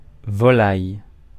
Ääntäminen
Synonyymit géline Ääntäminen France: IPA: [vɔ.laj] Haettu sana löytyi näillä lähdekielillä: ranska Käännöksiä ei löytynyt valitulle kohdekielelle.